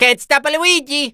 The sound Luigi makes when he is unstoppable in Super Mario Party Jamboree.